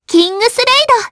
Frey-Vox_Kingsraid_jp_b.wav